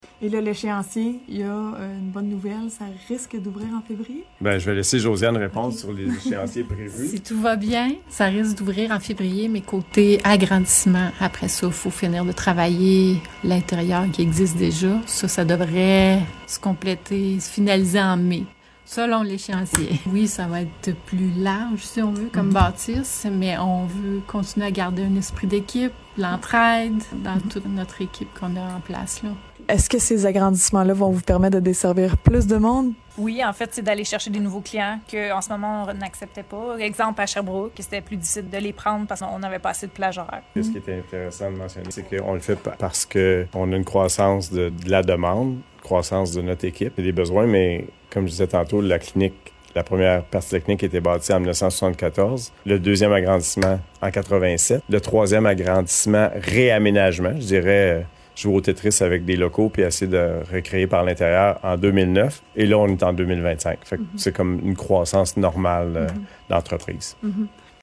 ENTREVUE-2.5.3-CLINIQUE-VET_01.mp3